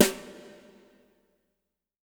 BW BRUSH02-R.wav